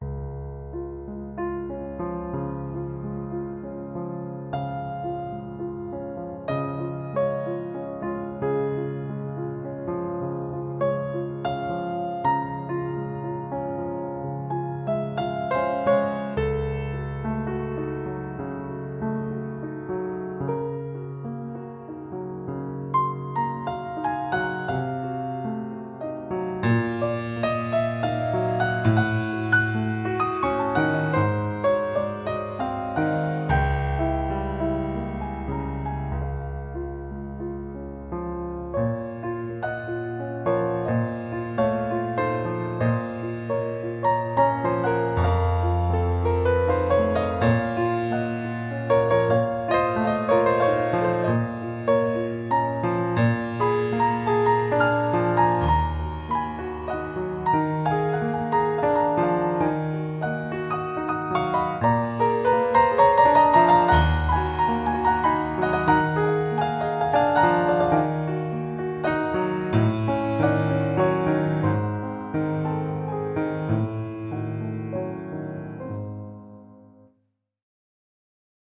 Impact Soundworks Pearl Concert Grand是一款优美的钢琴音色库，采样了一架雅马哈C7三角钢琴，拥有四种麦克风视角，每种都提供了独特而醇厚的声音。
- 雅马哈 C7 音乐厅录制
- 四个麦克风位置：关闭、踏板、舞台、大厅
- 半音键释放和踏板噪音